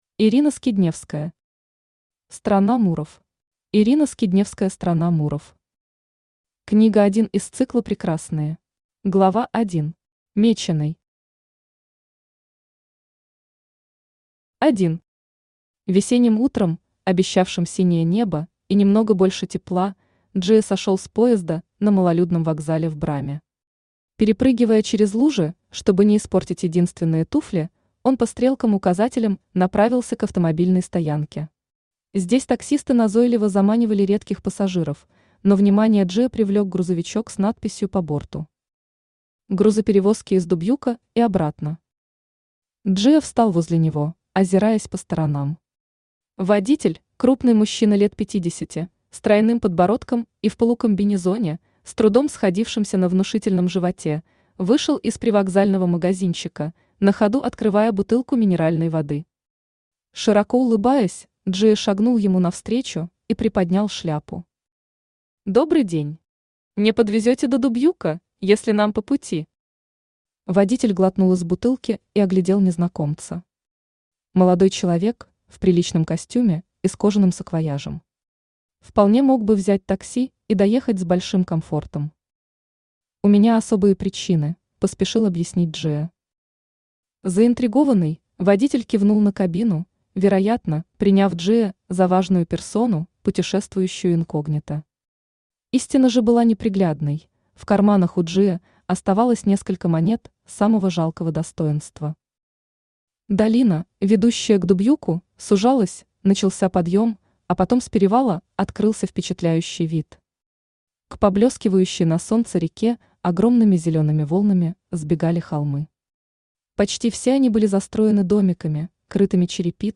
Аудиокнига Страна мурров | Библиотека аудиокниг
Aудиокнига Страна мурров Автор Ирина Владимировна Скидневская Читает аудиокнигу Авточтец ЛитРес.